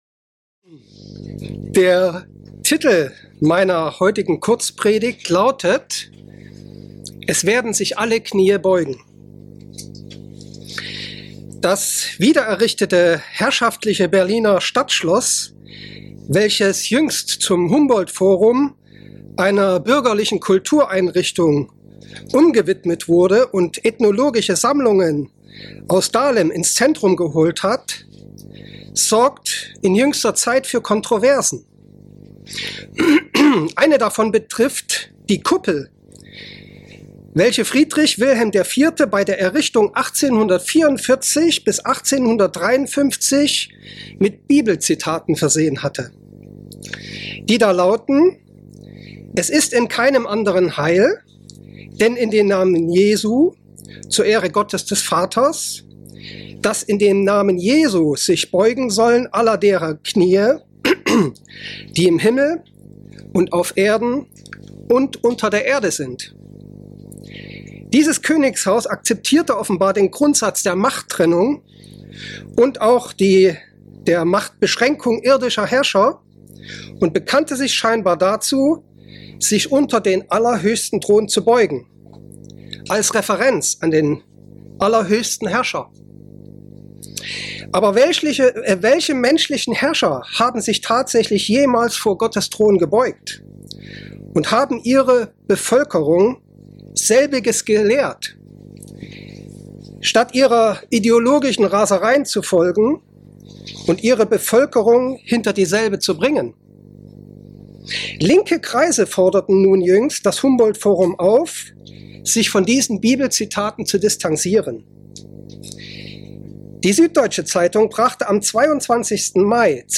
Diese Kurzpredigt beleuchtet einige Aspekte, warum heute im Prinzip nur einige Menschen ihre Knie vor Gott beugen, letztlich aber die allermeisten Menschen das Gleiche tun werden.